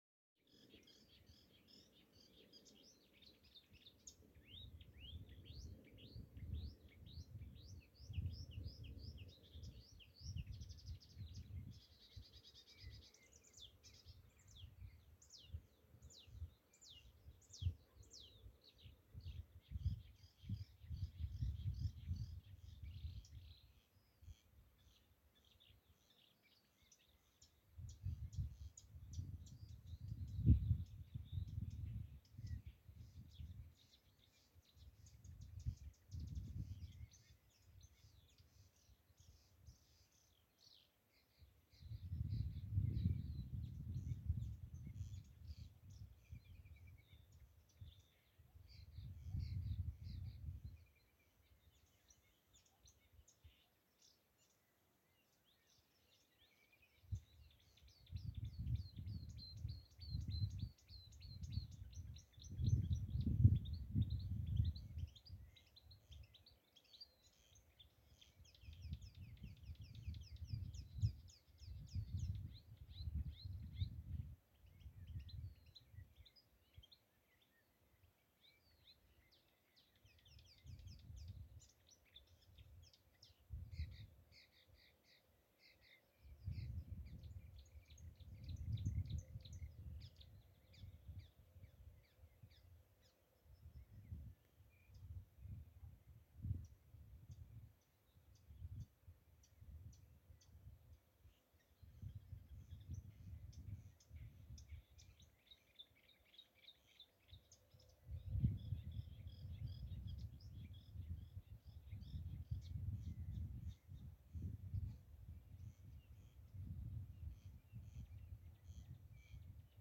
Птицы -> Славковые ->
болотная камышевка, Acrocephalus palustris
СтатусПоёт